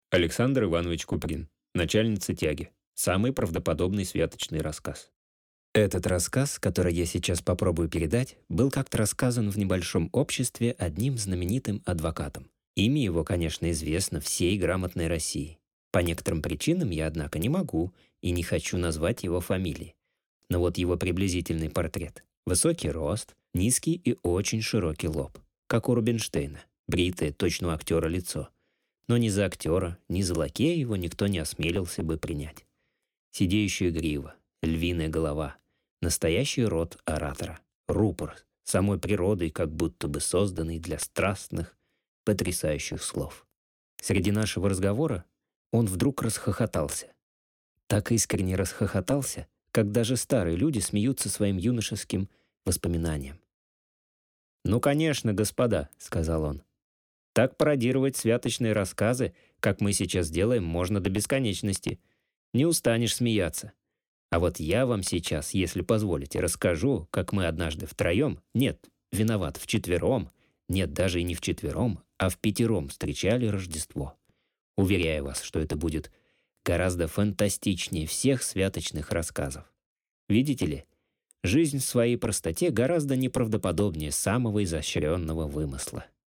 Аудиокнига Начальница тяги | Библиотека аудиокниг